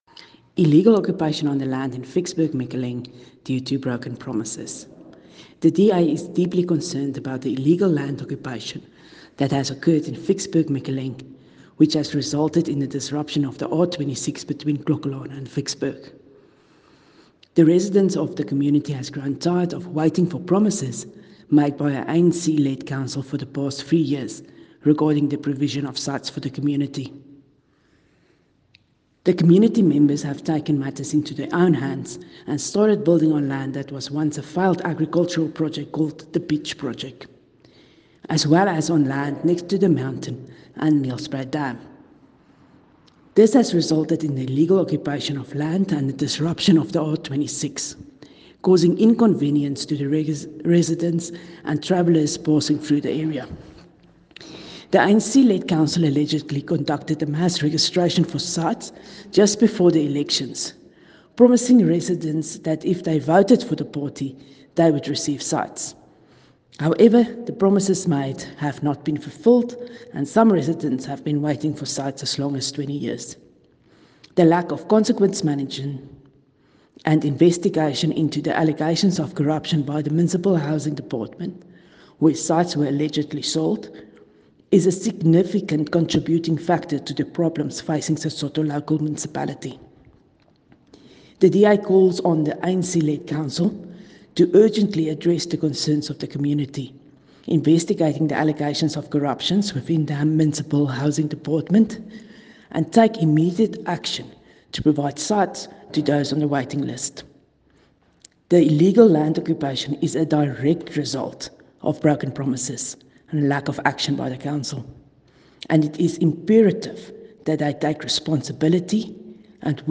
Afrikaans soundbites by Cllr Riette Dell and